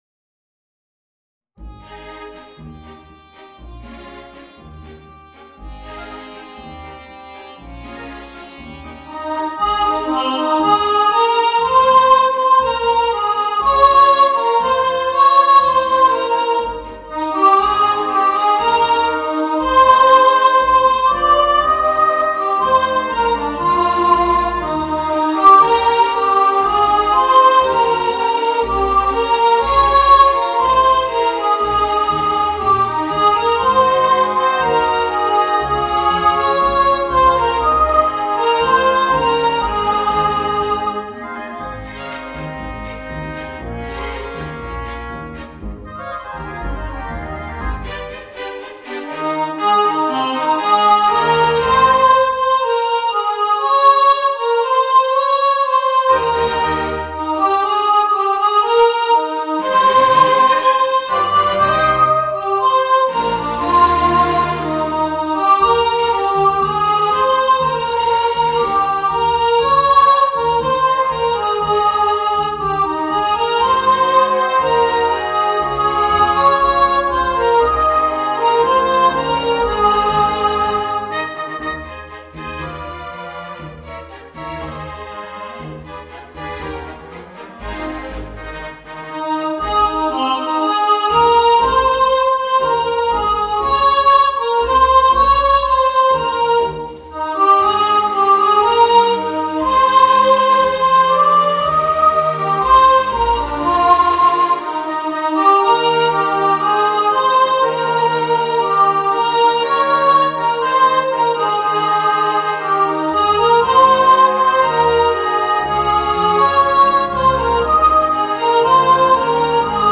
Voicing: Children's Choir and Orchestra